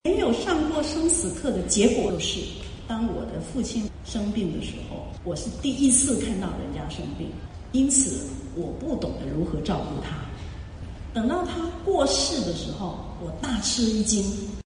在2019年10月12日于尔湾南海岸中华文化中心举行的读者见面会上，龙应台女士分享对亲情、生死的感悟